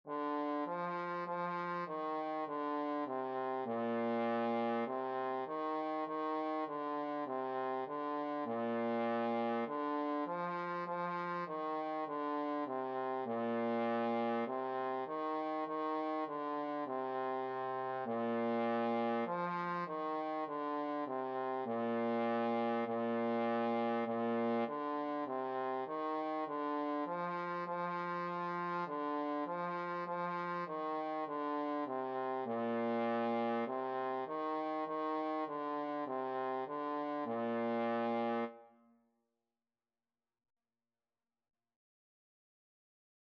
4/4 (View more 4/4 Music)
Bb3-F4
Trombone  (View more Beginners Trombone Music)
Classical (View more Classical Trombone Music)